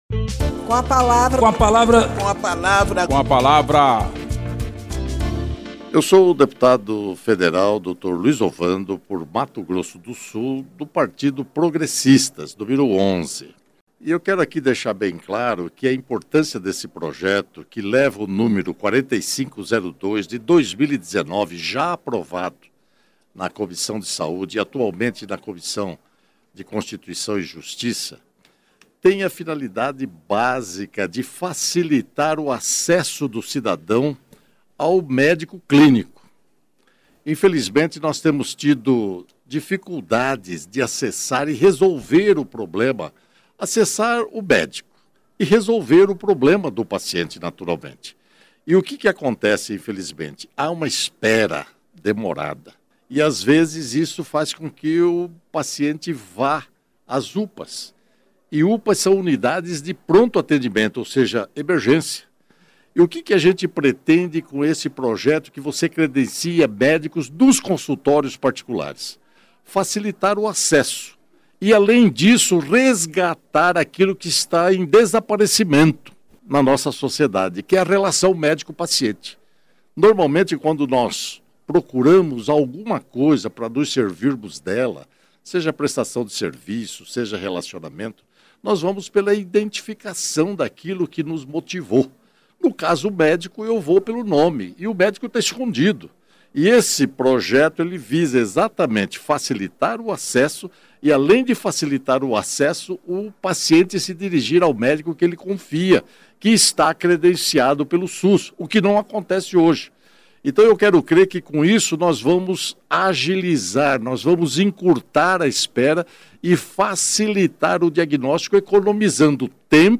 O deputado Dr. Luiz Ovando (PP-MS) ressalta o impacto do projeto que facilita o acesso do cidadão ao médico clínico.